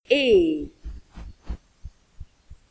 ay